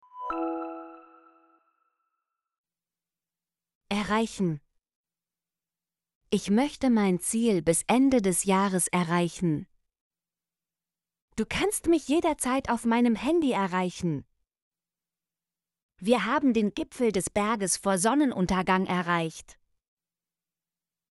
erreichen - Example Sentences & Pronunciation, German Frequency List